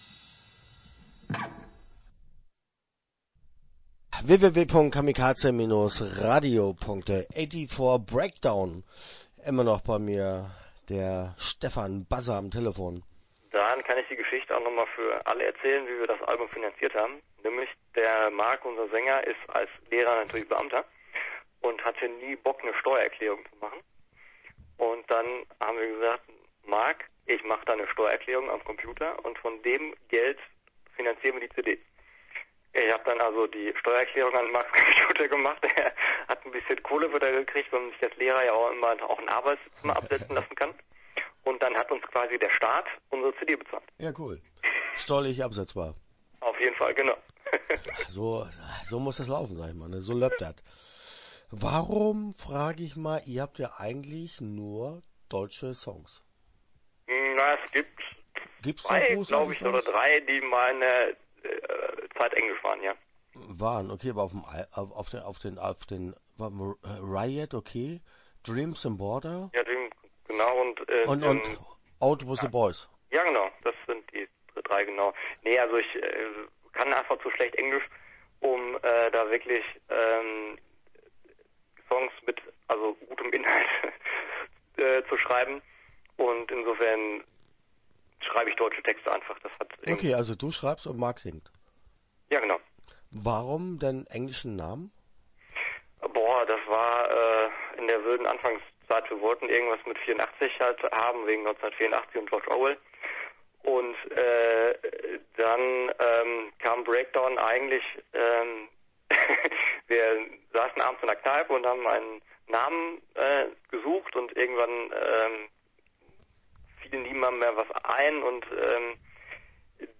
84breakdown - Interview Teil 1 (13:34)